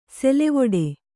♪ selevoḍe